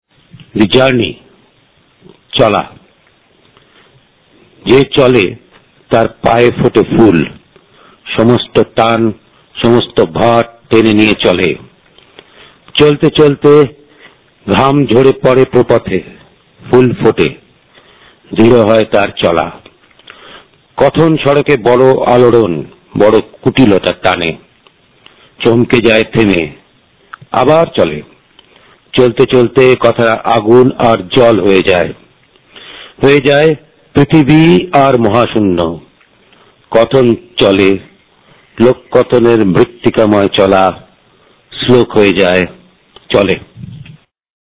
"The Journey"(Cholaa) Audiovisual poem recited in Bengali